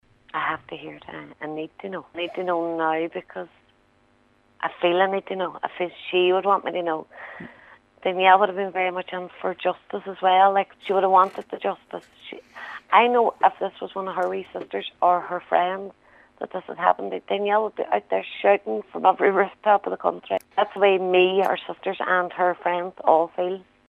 In a previous interview on Highland Radios Nine Till Noon Show